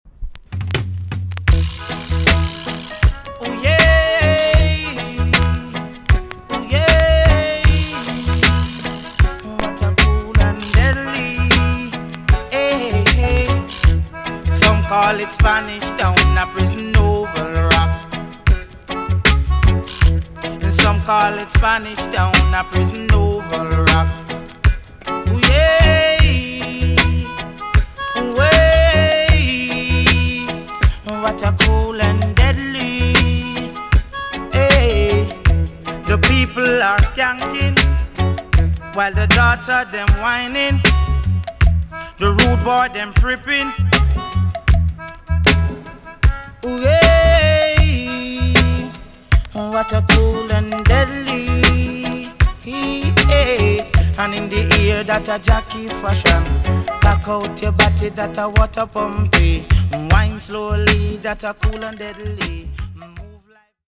TOP >DISCO45 >80'S 90'S DANCEHALL
EX-~VG+ 少し軽いチリノイズがありますが音はキレイです。